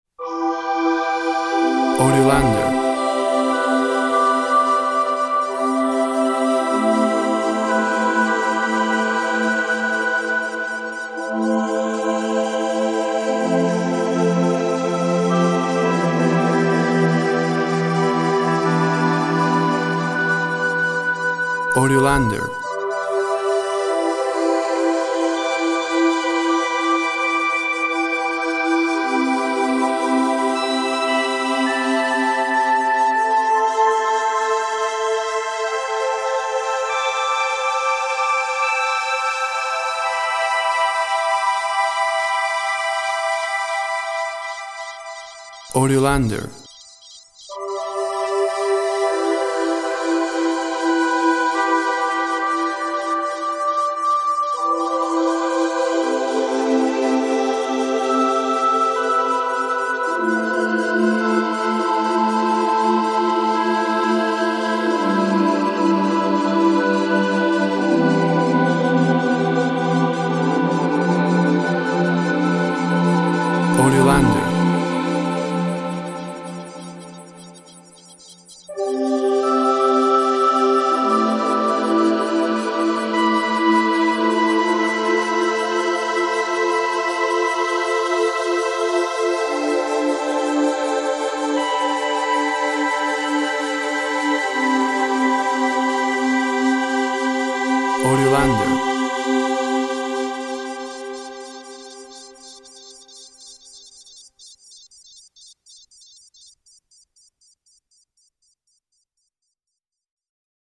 Calming night sounds with solo.
Tempo (BPM) 52